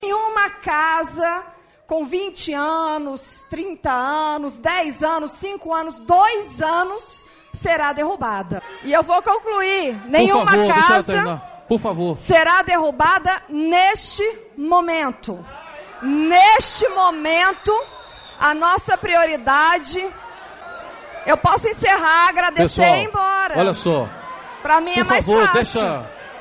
Ouça o que a Bruna disse ao ser questionada na CLDF na época: